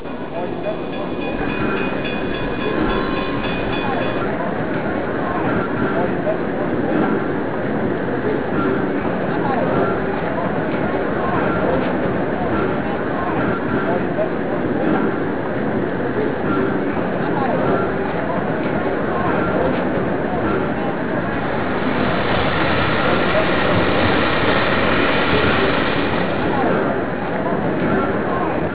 STATION SOUNDS
Station Steam sounds  NECRHQ900